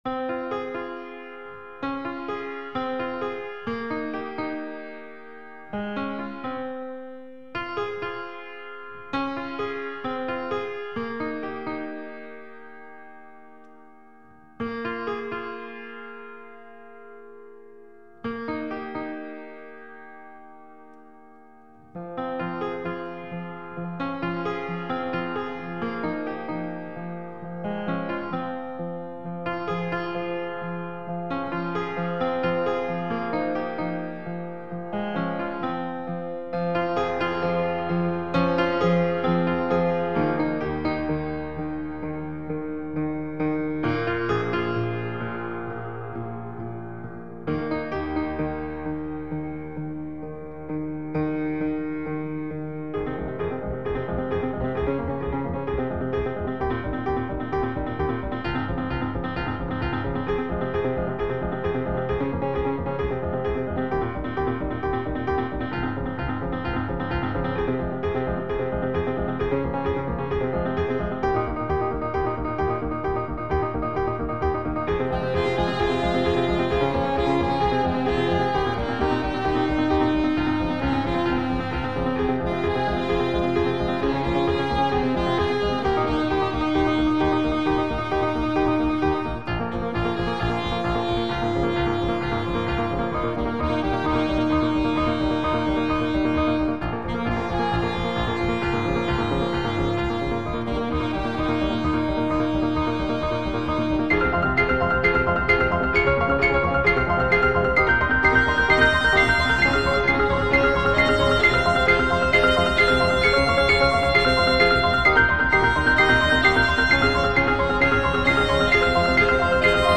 Minimal Music, Ambient, Contemporary Classical, New Age